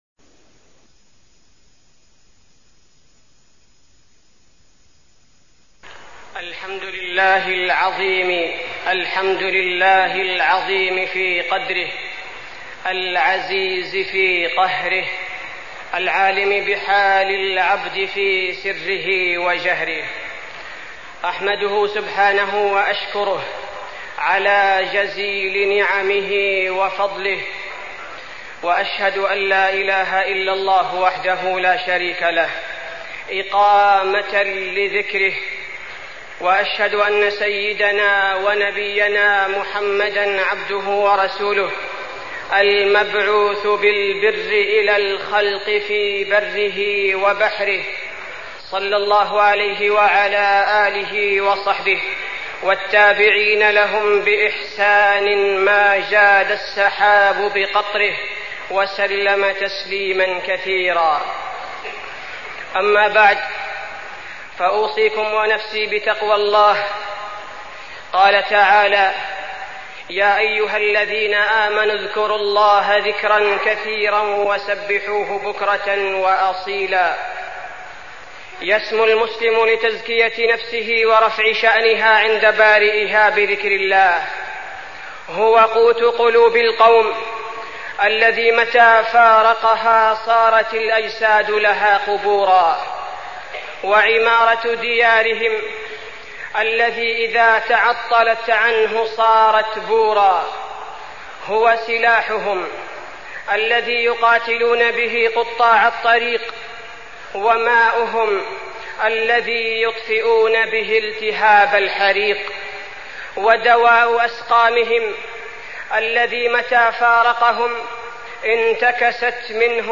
تاريخ النشر ٢٧ ربيع الثاني ١٤١٦ هـ المكان: المسجد النبوي الشيخ: فضيلة الشيخ عبدالباري الثبيتي فضيلة الشيخ عبدالباري الثبيتي ذكر الله عز وجل